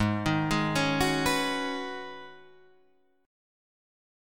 G#m11 Chord